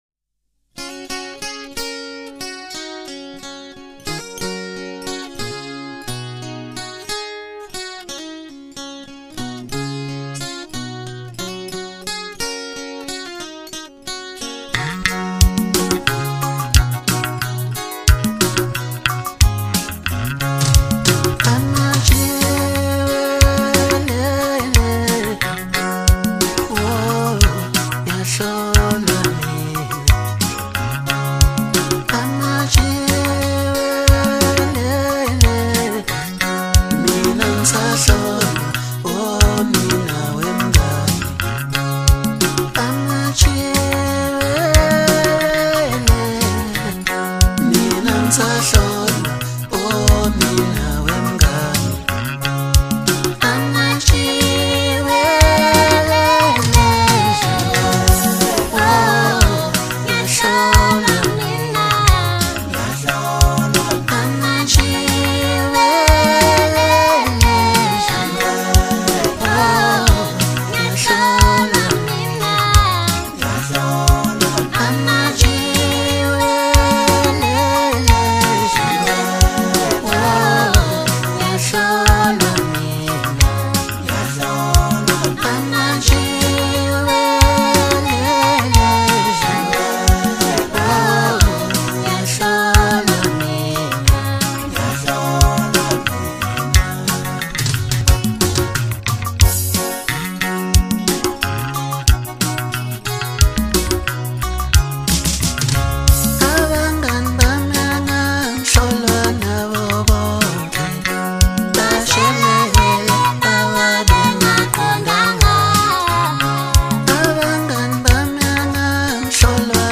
Home » Hip Hop » Latest Mix » Maskandi